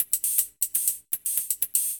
UHH_ElectroHatD_120-05.wav